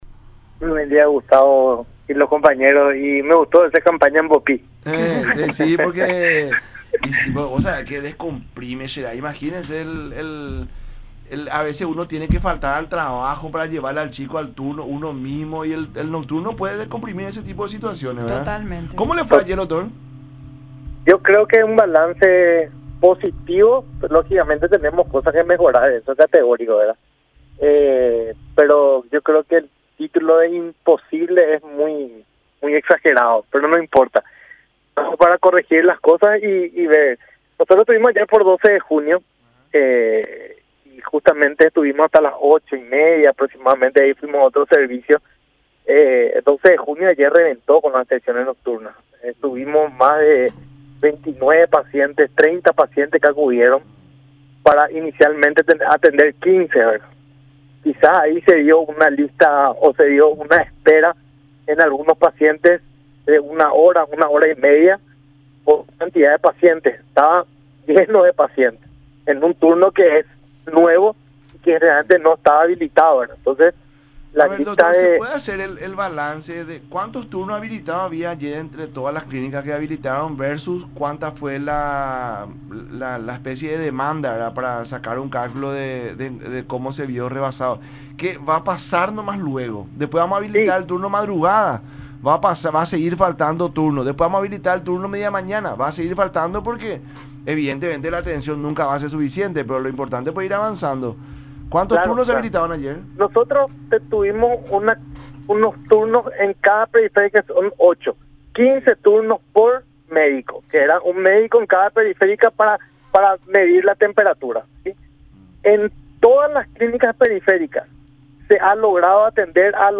“Tuvimos unos turnos en cada periferia, 15 turnos por médico, en todas se ha logrado atender a los pacientes”, agregó en el programa “La Unión Hace La Fuerza” por radio La Unión y Unión Tv.